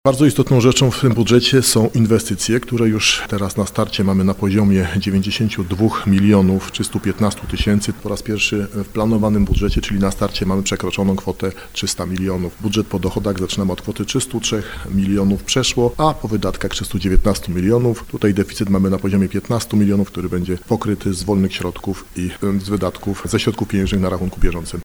– Ten budżet jest w jakimś sensie historyczny – komentuje starosta nowosądecki Tadeusz Zaremba.